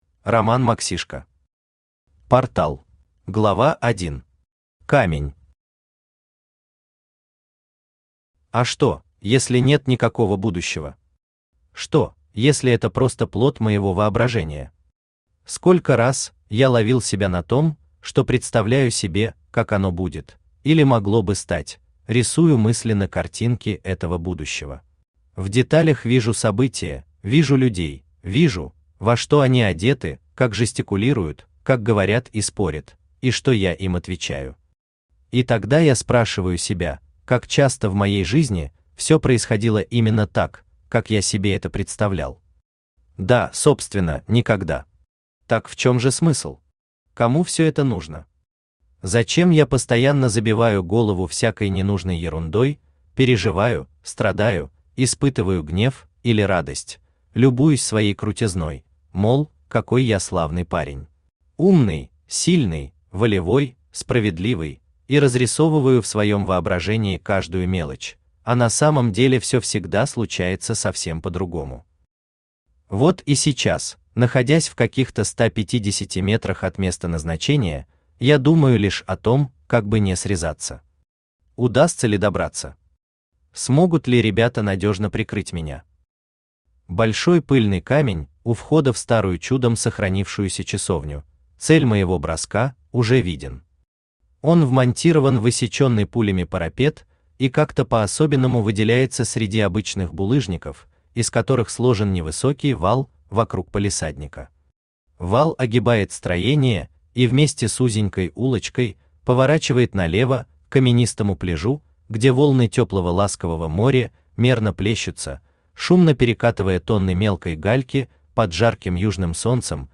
Aудиокнига Портал Автор Роман Максишко Читает аудиокнигу Авточтец ЛитРес. Прослушать и бесплатно скачать фрагмент аудиокниги